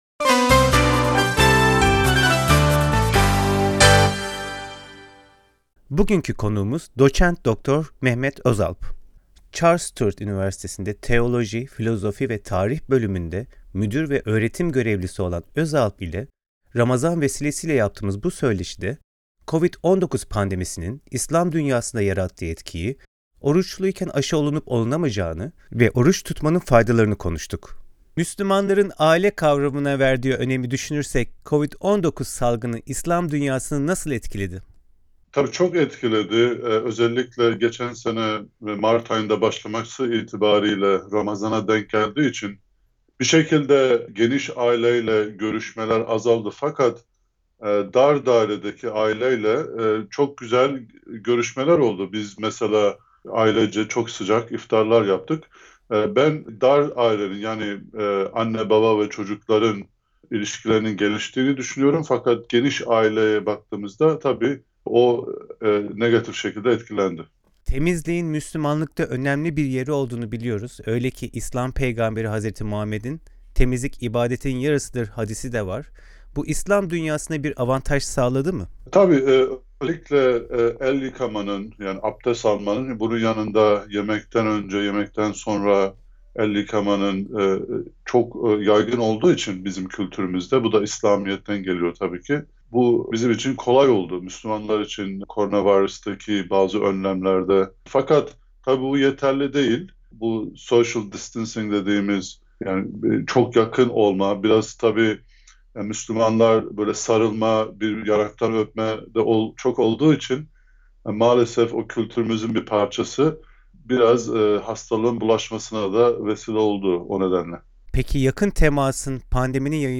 söyleşide